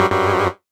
incorrect.ogg